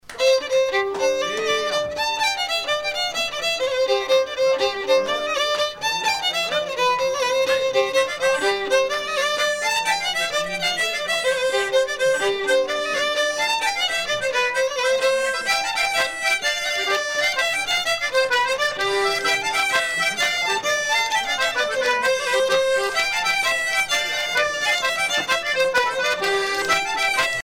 danse : matelote
Pièce musicale éditée